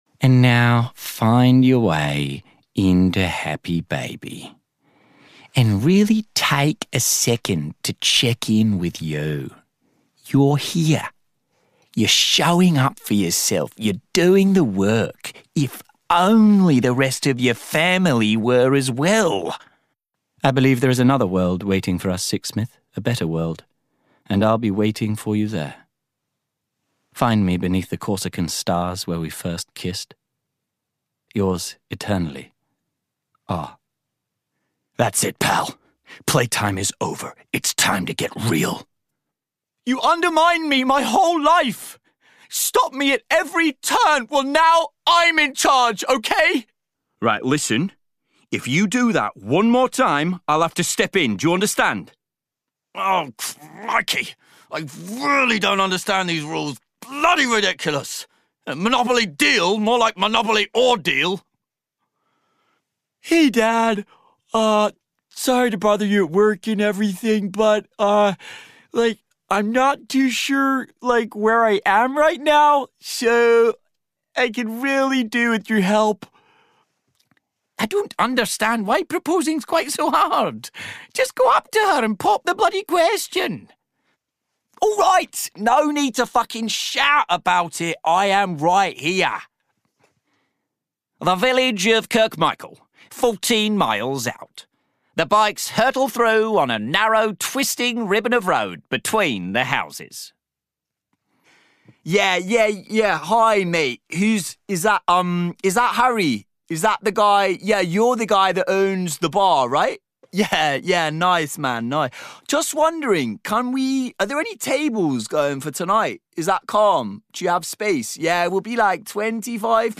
Character Reel
• Native Accent: RP